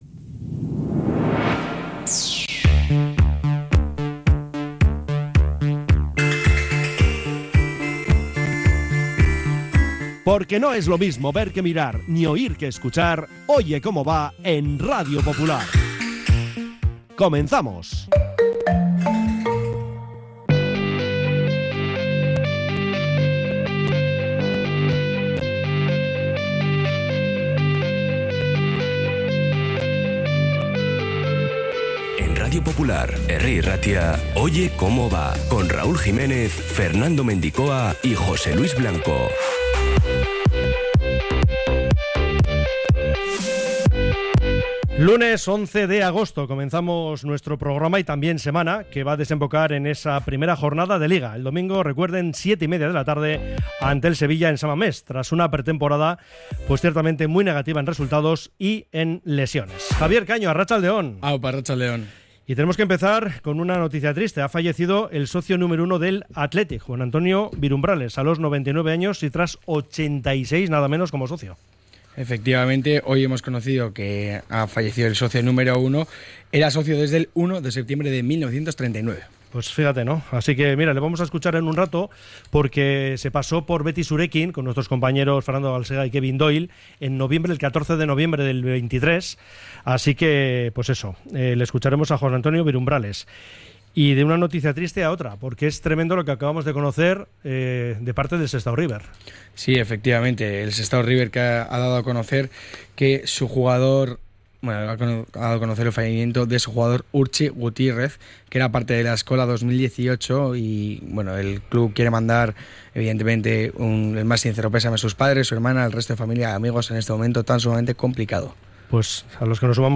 Fin a una mala pretemporada, actualidad Athletic, entrevista